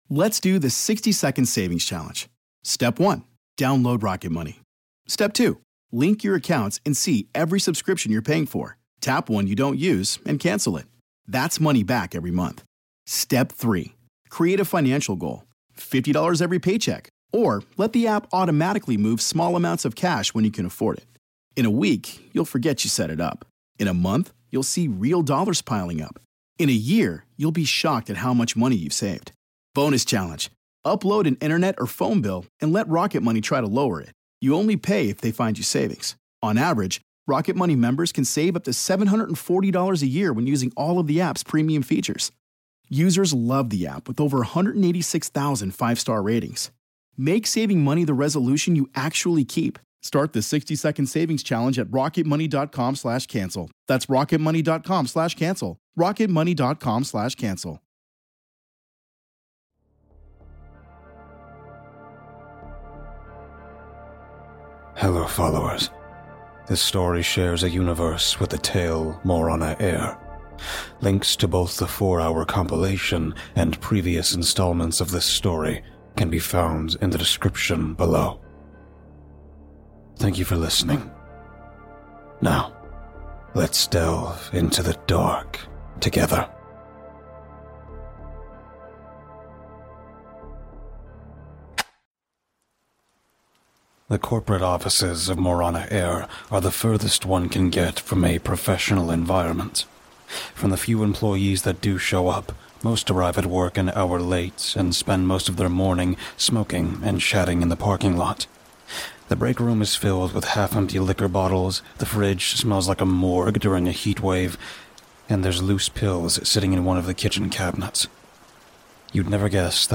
Intro Music- “80s Retro Horror Synth